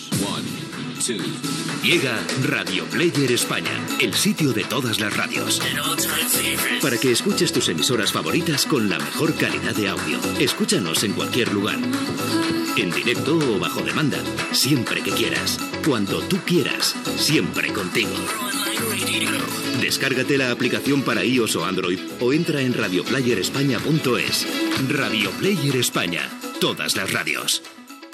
Anunci de la plataforma radiofònica per Internet Radioplayer España